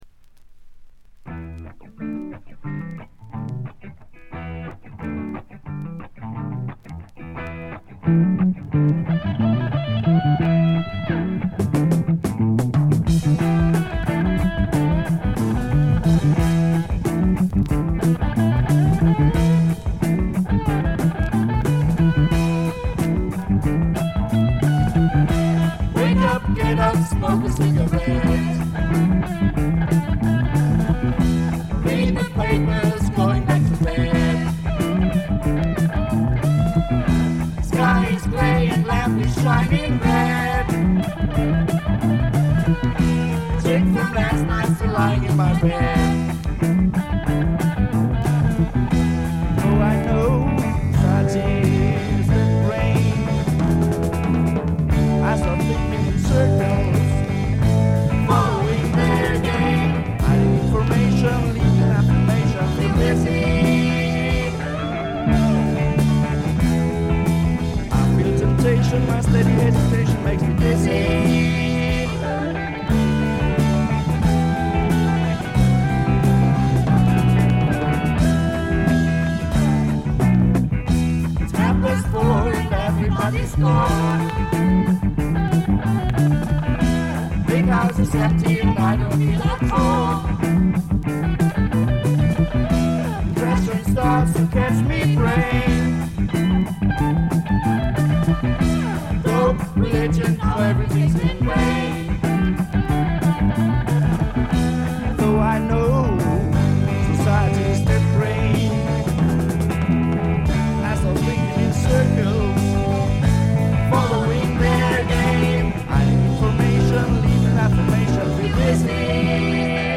全体にバックグラウンドノイズが出ていますが静音部で分かる程度。散発的なプツ音が2-3回ほど。
ジャーマン・アンダーグラウンド・プログレッシヴ・サイケの雄が放った名作。
試聴曲は現品からの取り込み音源です。